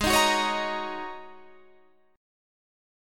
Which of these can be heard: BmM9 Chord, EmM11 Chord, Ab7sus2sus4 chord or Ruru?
Ab7sus2sus4 chord